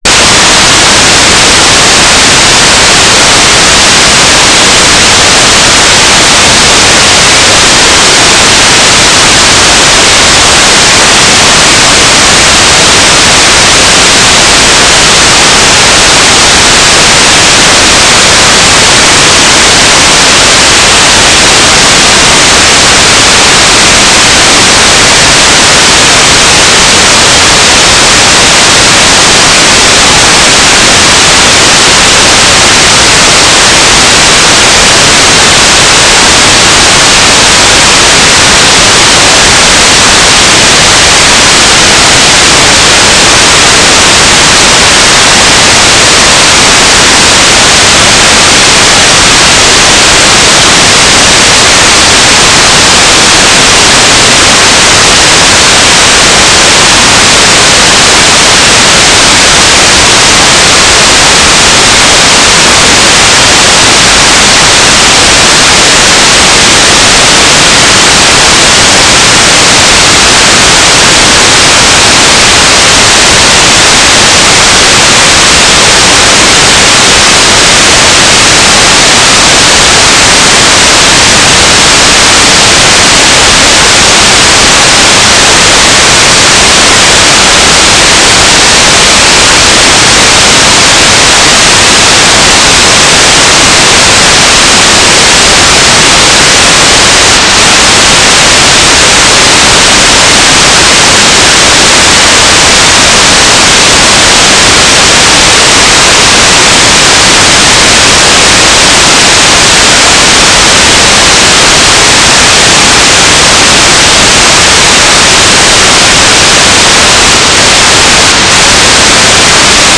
"transmitter_description": "Mode U 9k6 FSK AX.25",
"transmitter_mode": "FSK",